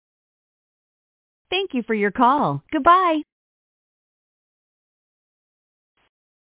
Calling two are code numbers with the same caller ID will return this recording::